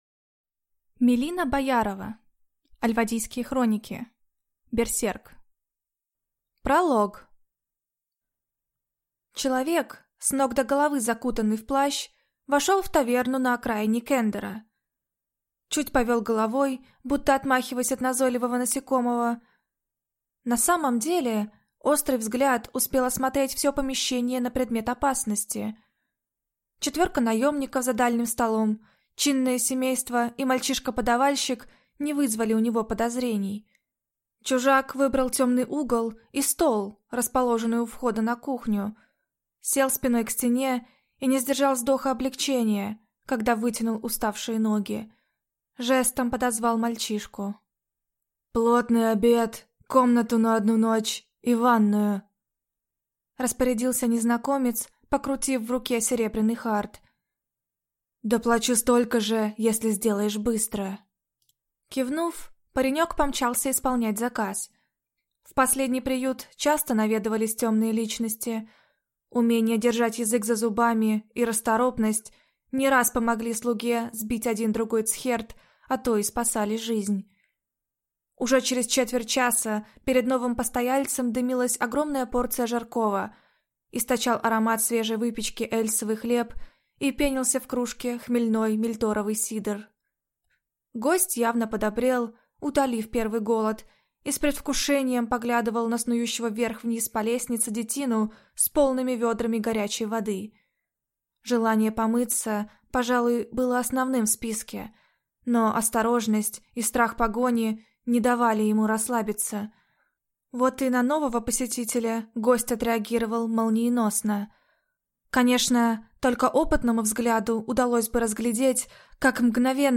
Аудиокнига Берсерк | Библиотека аудиокниг
Прослушать и бесплатно скачать фрагмент аудиокниги